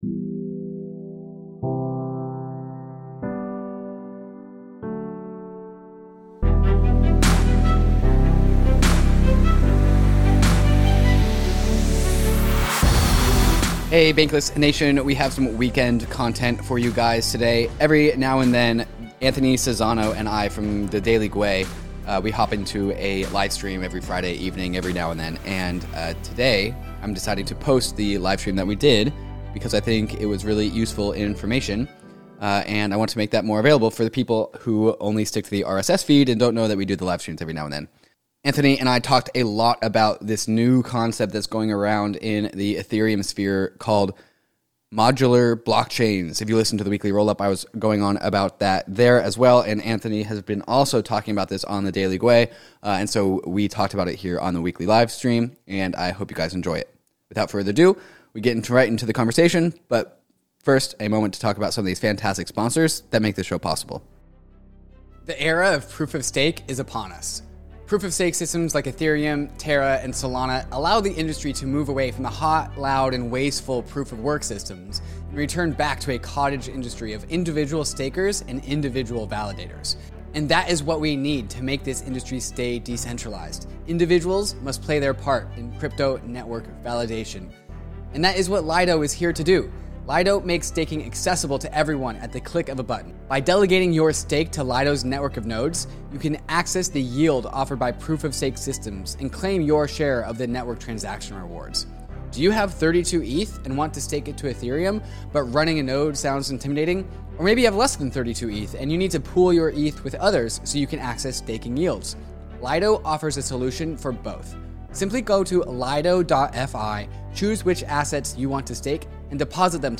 We thought the conversation was especially useful this week, so we're putting it on the podcast feed.